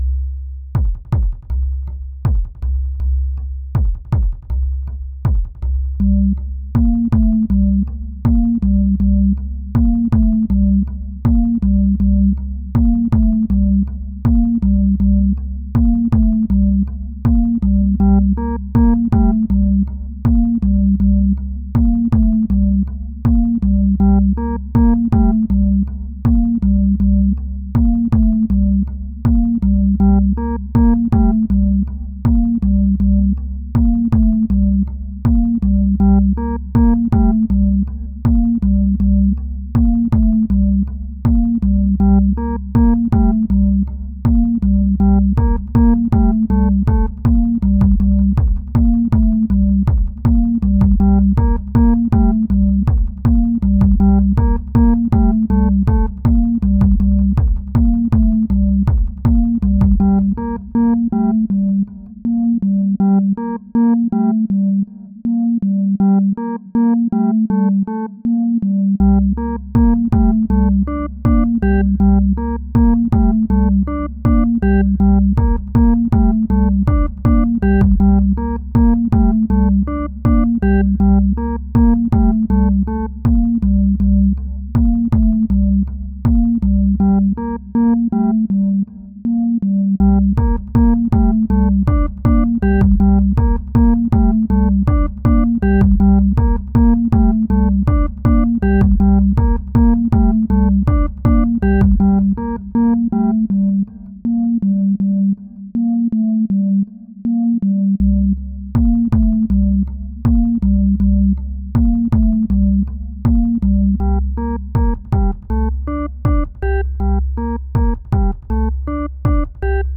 Pieza de Ambient Techno
tecno
melodía
repetitivo
sintetizador